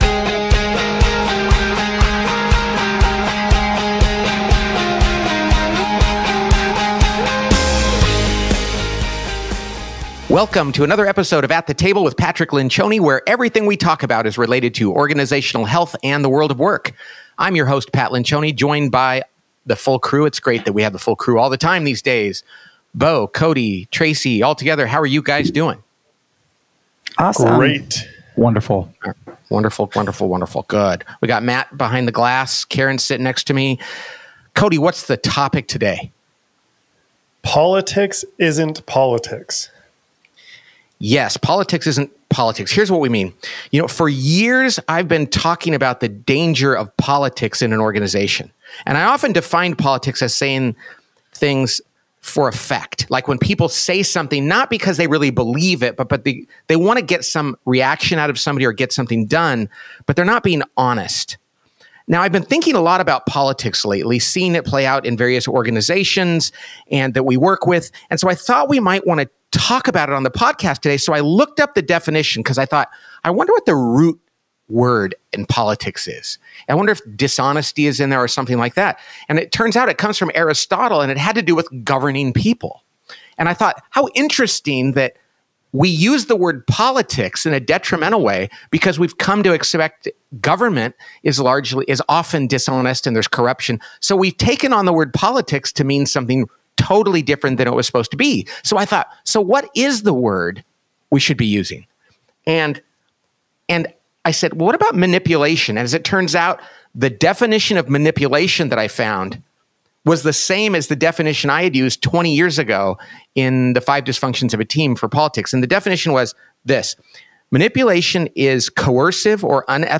This week, Pat and the team discuss the implications of manipulative behavior in the workplace.